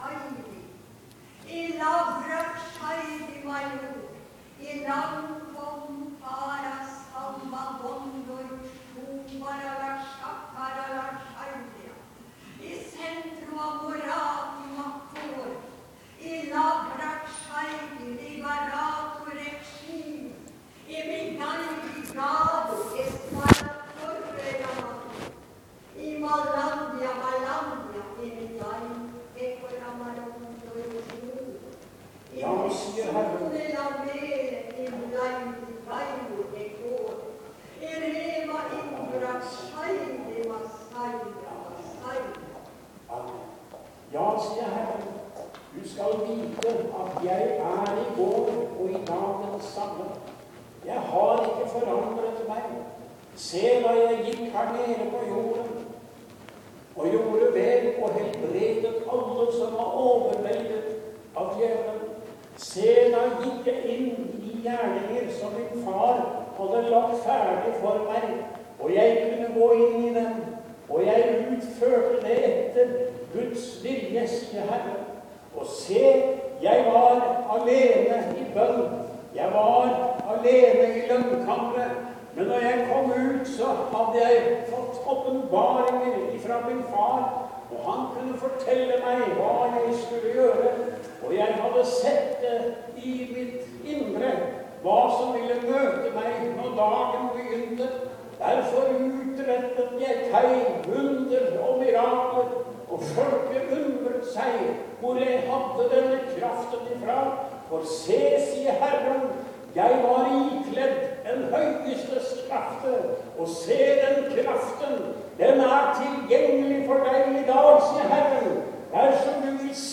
Tale
Tungetale